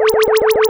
Zapps_02.wav